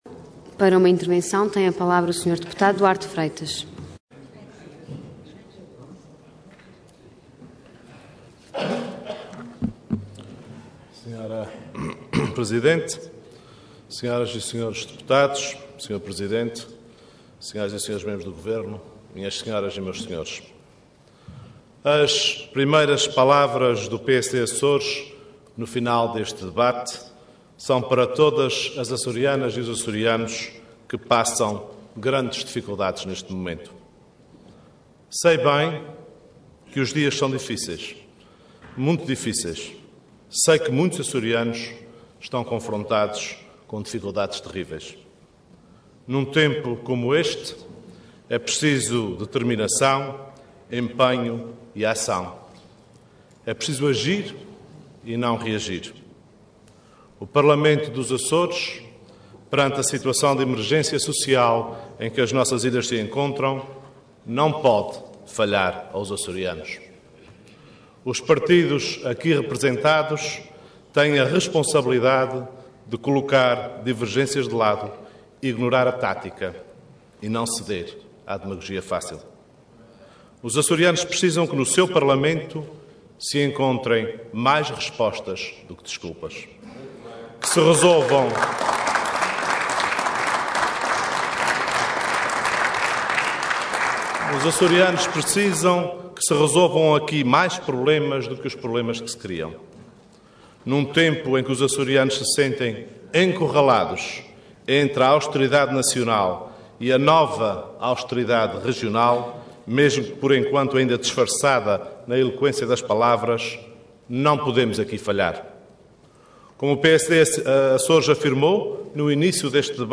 Intervenção Intervenção de Tribuna Orador Duarte Freitas Cargo Deputado Entidade PSD